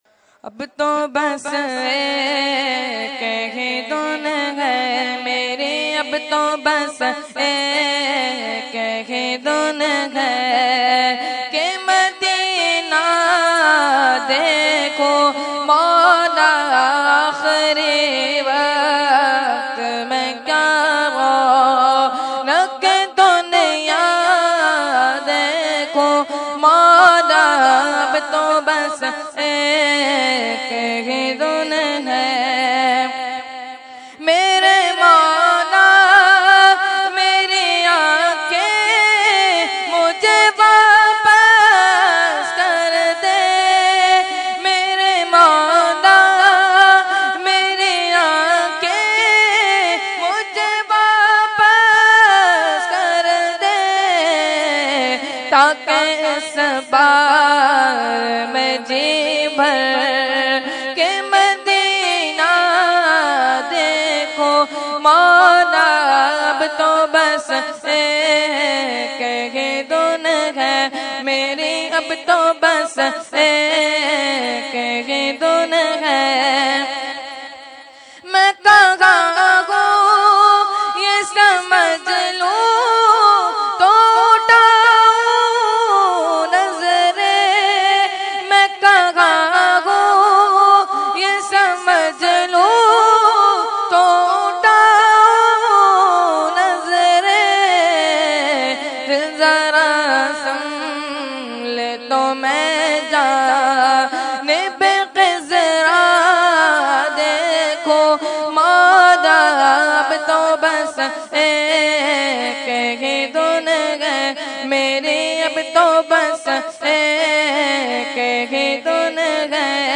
Category : Manqabat | Language : UrduEvent : Urs e Makhdoom e Samnani 2015